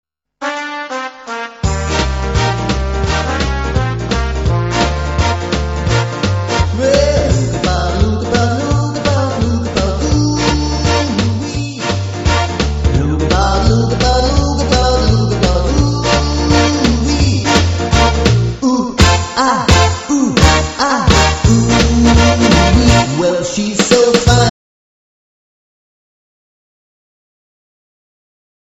Party-Music-Band